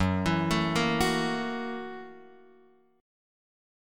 F#7#9 chord